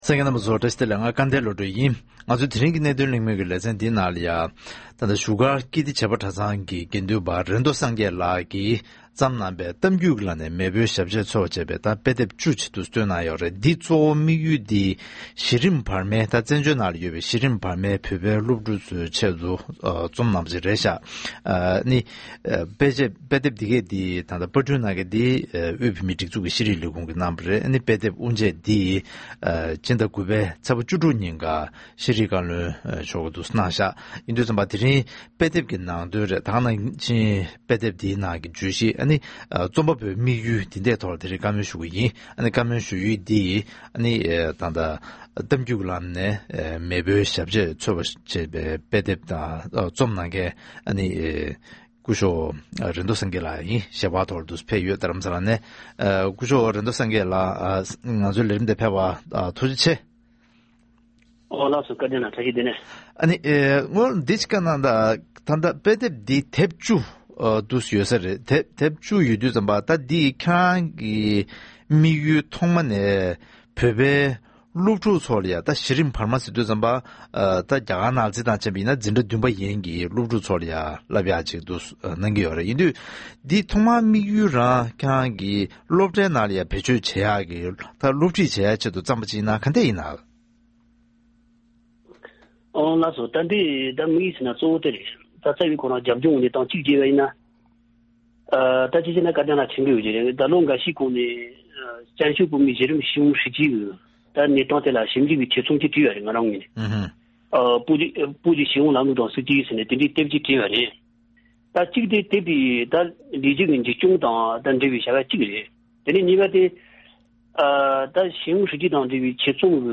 ཐེངས་འདིའི་གནད་དོན་གླེང་མོལ་གྱི་ལེ་ཚན་ནང་།